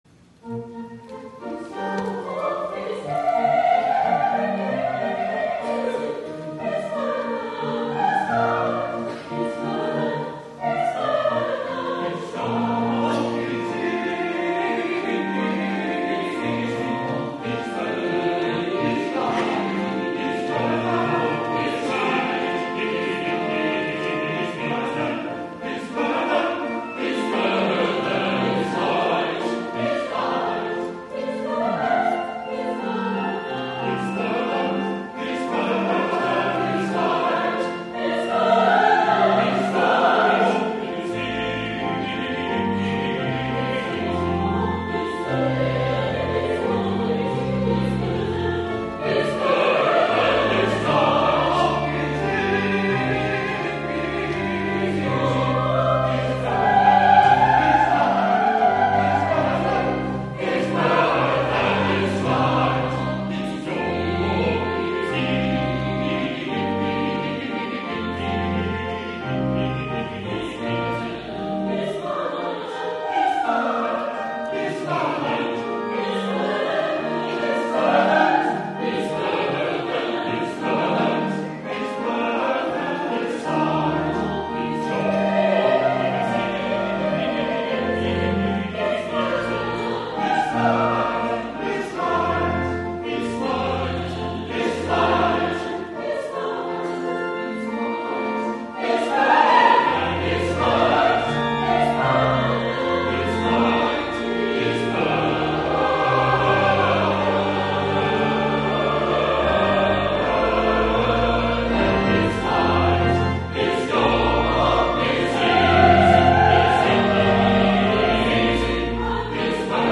THE MUSICAL OFFERING MESSIAH (1741) George Frideric Handel
11. Chorus: